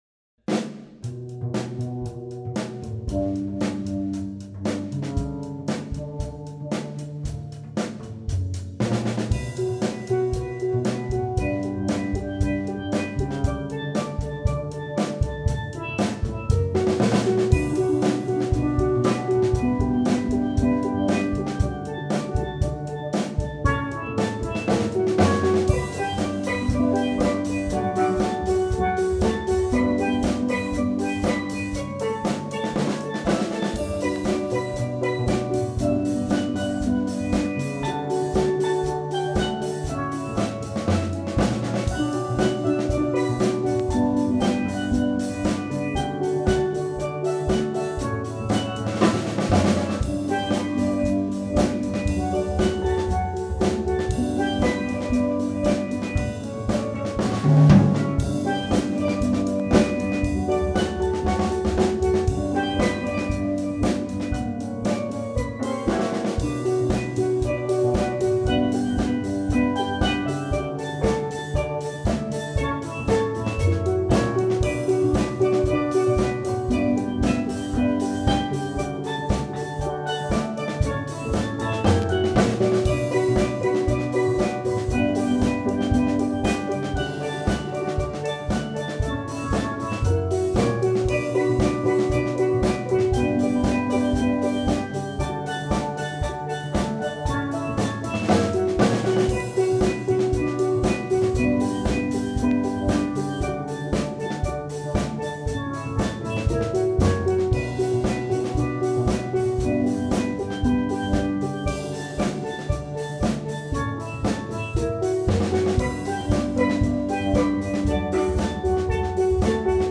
Check out a few samples from the UAH Steel Drum Band: